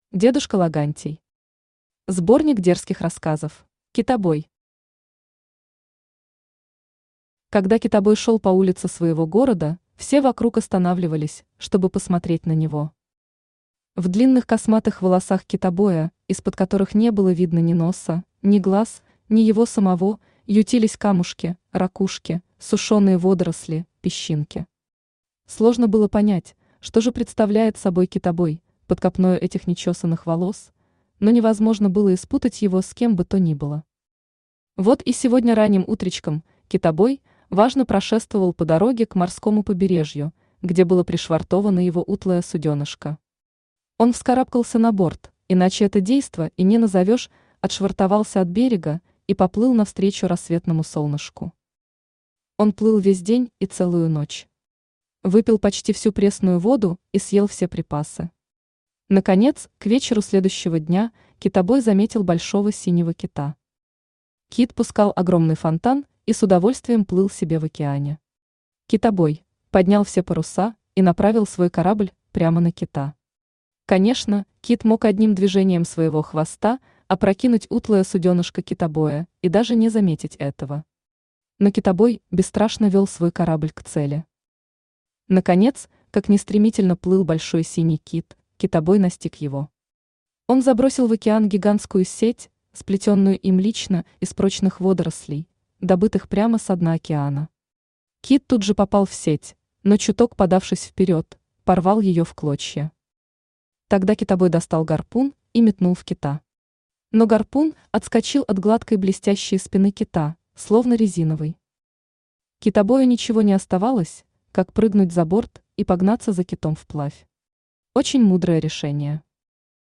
Аудиокнига Сборник дерзких рассказов | Библиотека аудиокниг
Aудиокнига Сборник дерзких рассказов Автор дедушка Логантий Читает аудиокнигу Авточтец ЛитРес.